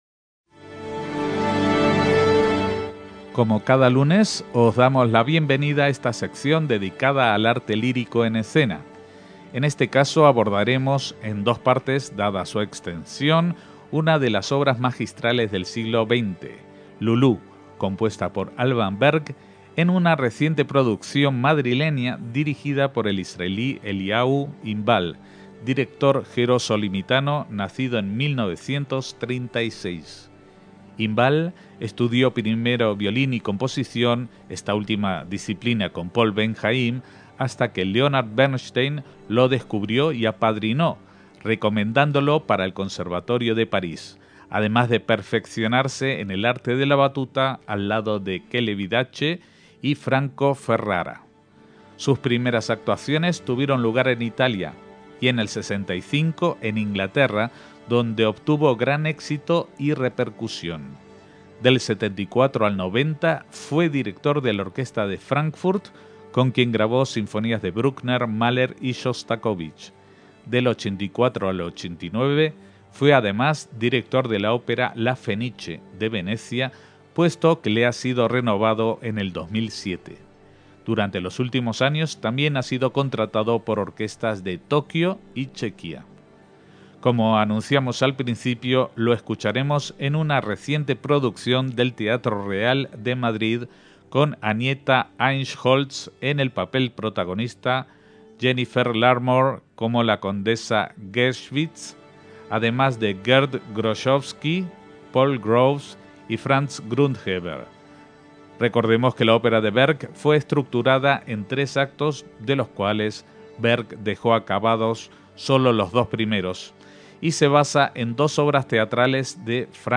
en una producción de 2009 en el Teatro Real de Madrid
voces solistas